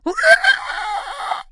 可怕的怪物 第一卷 " 怪物的咆哮 14
描述：怪物/僵尸的声音，耶！我猜我的邻居现在关注僵尸入侵（我在我的壁橱里记录了我的怪物声音）。 用RØDENT2A录制。
标签： 怪物 怪物 天启 入侵 僵尸 尖叫 死了 恐怖 怪物 低吼 可怕
声道立体声